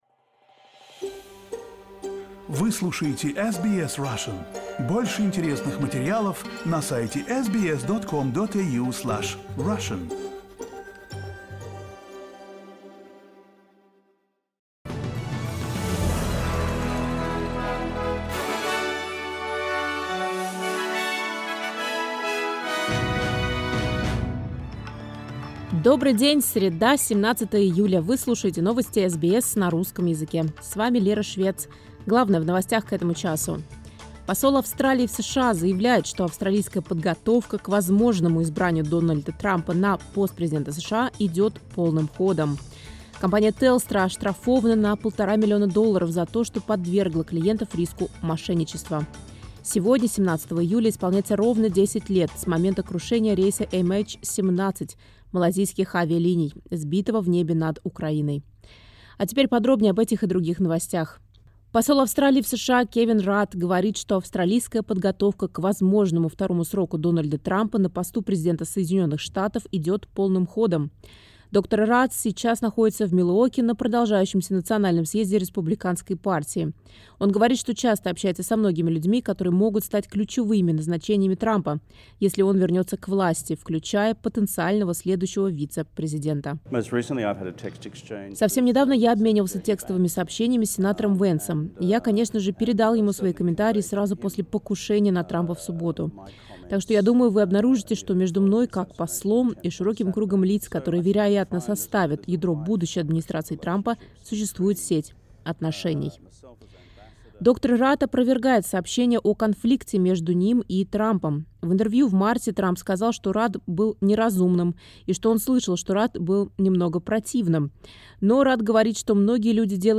SBS News in Russian — 17.07.2024